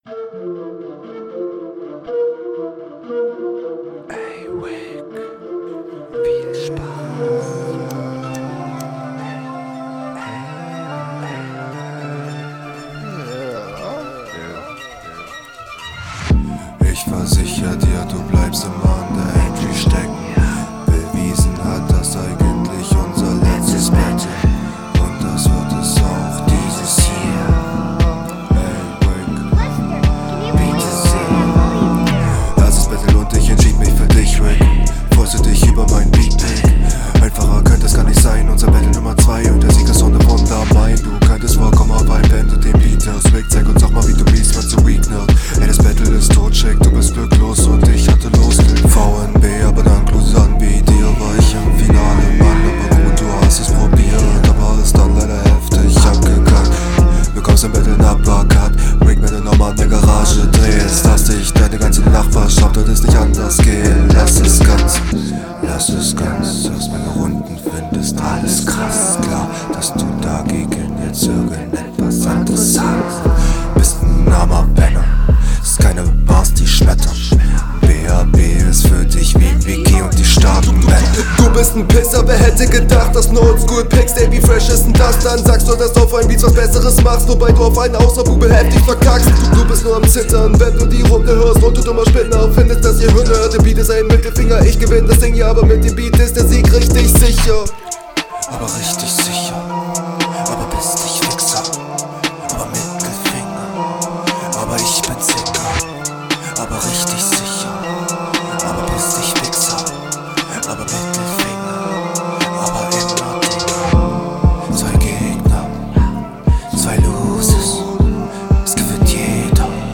Die Idee find ich cool. Mische auch wieder besser.
uh 2:40 geil. yeeeah autotune im Intro kommt gut dann killst du aber vorm einsteig …